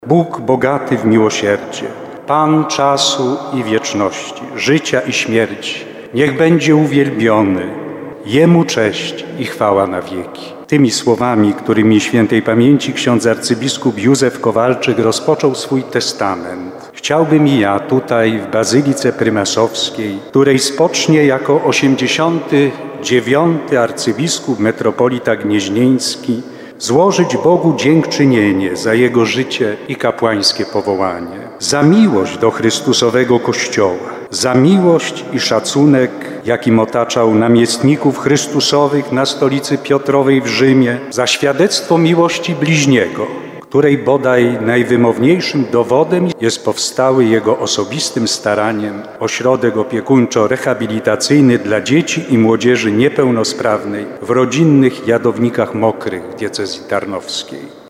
Mszy pogrzebowej w Bazylice Prymasowskiej przewodniczył abp Wojciech Polak, metropolita gnieźnieński, prymas Polski.
Rozpoczynając homilię abp Polak przywołał pierwsze słowa testamentu swojego poprzednika.
29kazanie.mp3